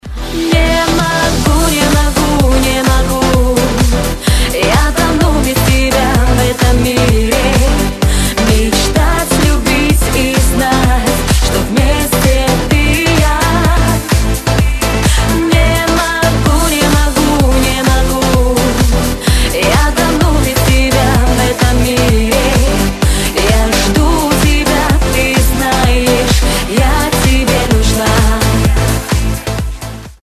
• Качество: 256, Stereo
поп
женский вокал
dance
vocal